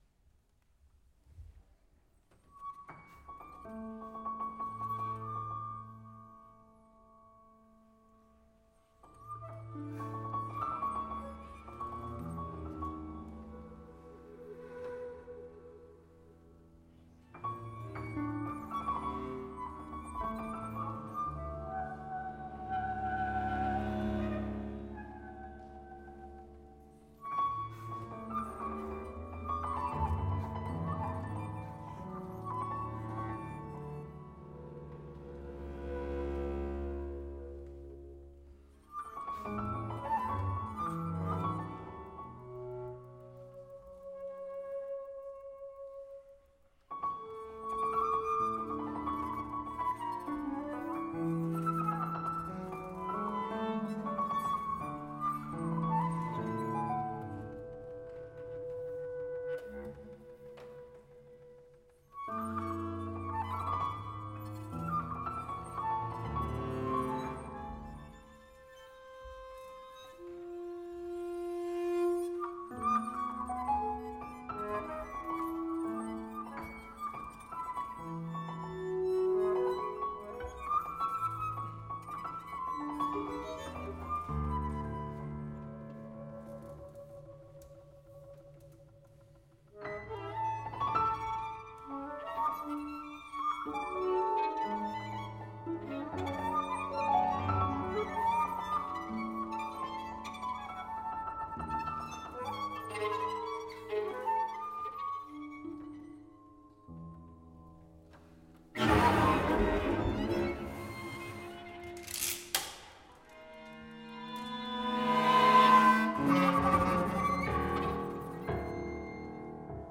Venice Biennale 2014
alto flute, bass clarinet, violin, cello, piano